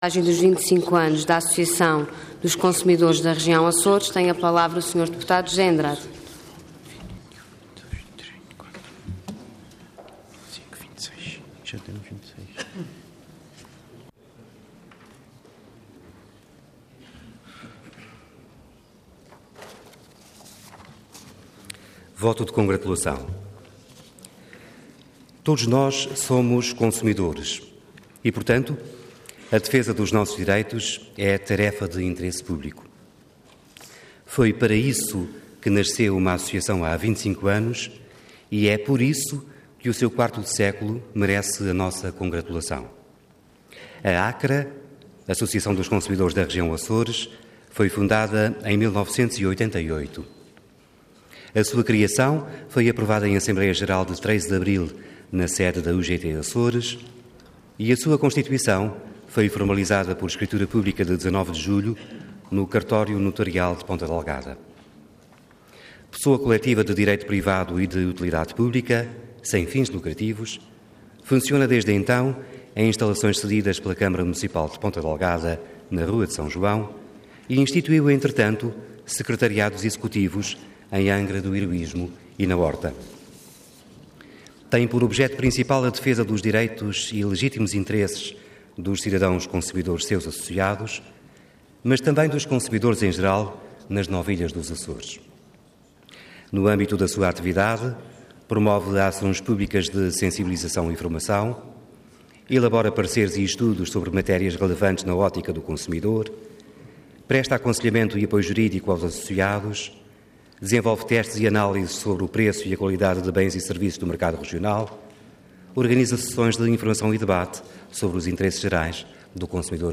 Intervenção Voto de Congratulação Orador José Andrade Cargo Deputado Entidade PSD